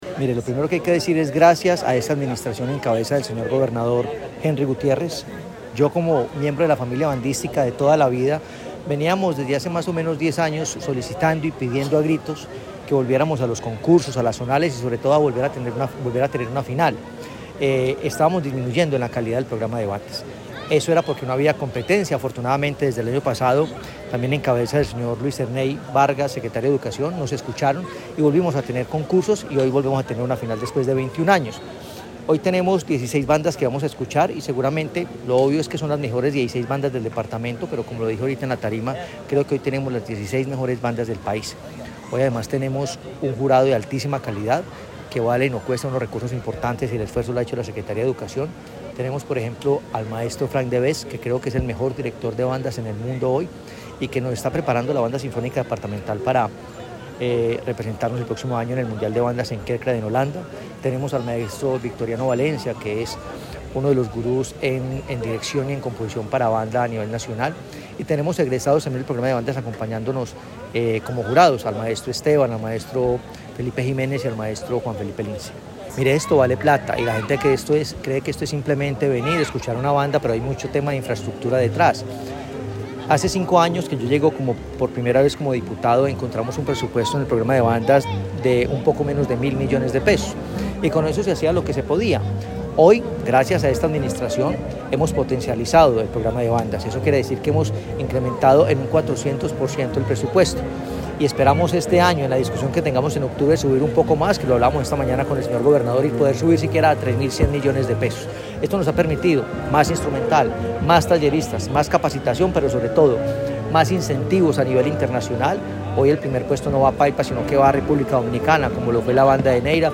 Diputado Hernán Alberto Bedoya.